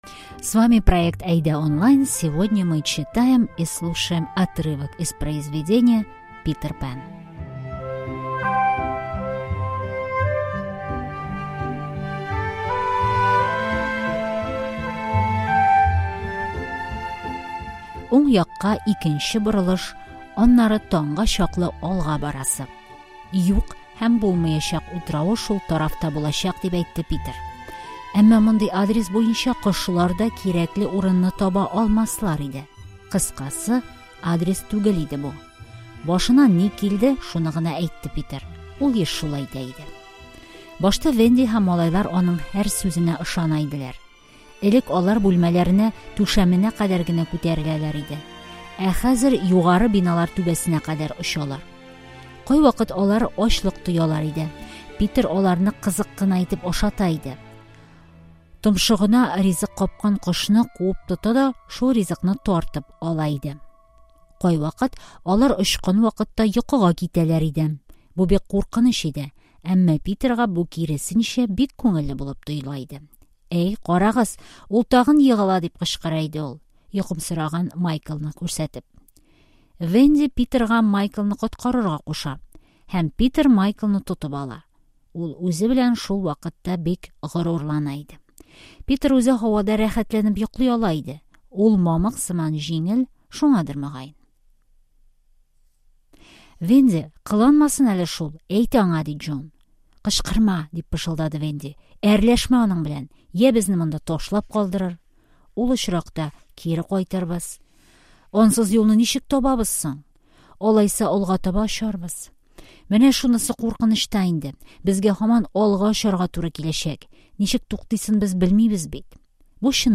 Специально для наших читателей мы перевели отрывок из сказки "Питер Пэн". Мы максимально упростили текст, записали красивую аудиосказку, добавили перевод основных фраз и тест по тексту.